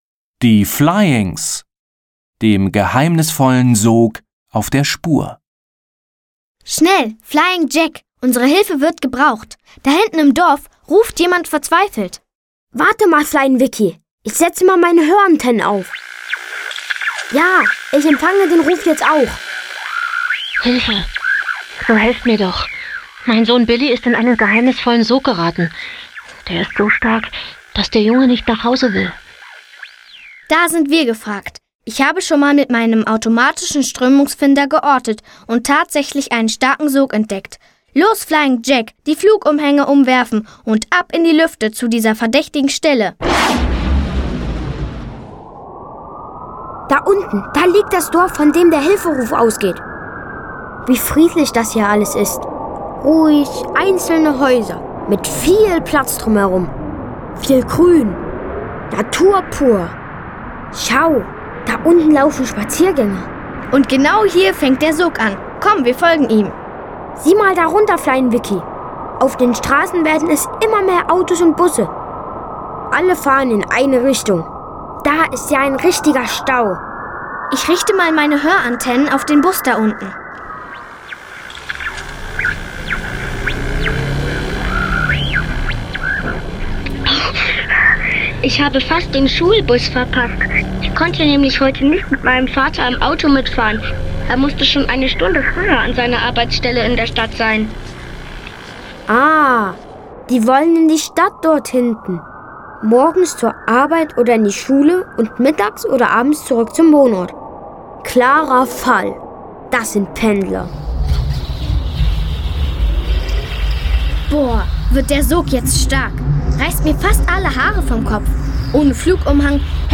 Hörspiel "Die Flyings: dem geheimnisvollen Sog auf der Spur"